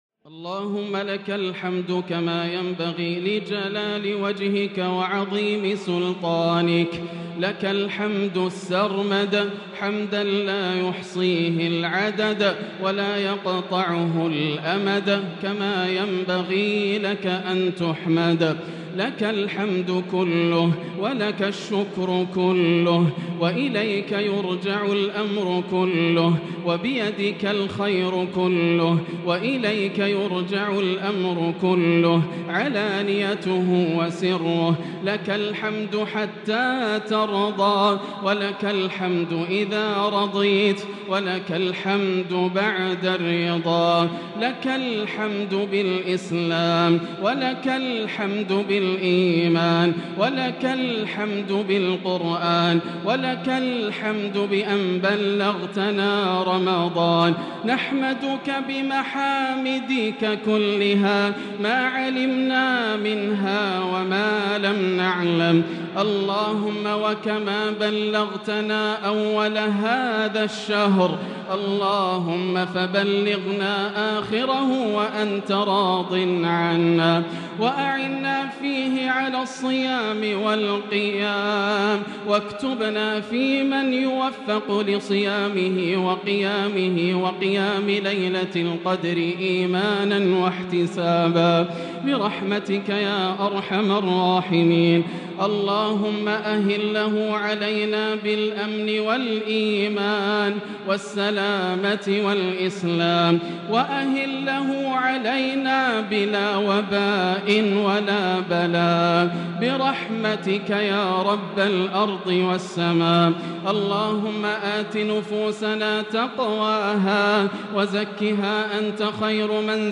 دعاء ليلة 2 رمضان 1443هـ | Dua for the night of 2 Ramadan 1443H > تراويح الحرم المكي عام 1443 🕋 > التراويح - تلاوات الحرمين